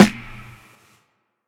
AGJDK_CLICK_SNR.wav